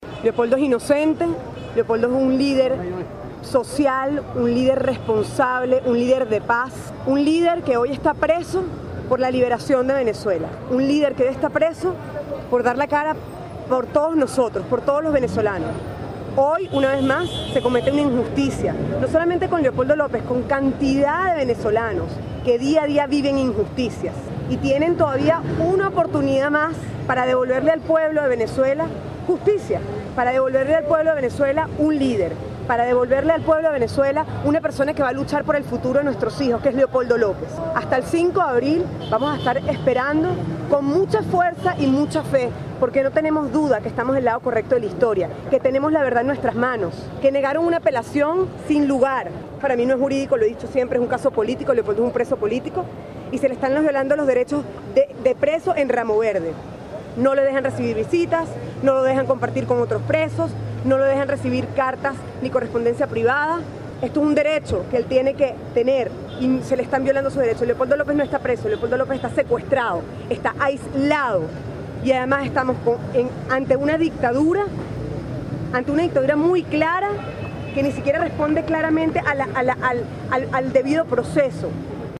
El líder opositor Leopoldo López continuará tras las rejas, luego que la sala tres de la Corte de Apelaciones del Circuito Judicial Penal de Caracas negó este viernes una petición de excarcelación, presentada por la defensa, para ser juzgado sin estar privado de su libertad. Su esposa Lilián Tintori dio declaraciones a la prensa.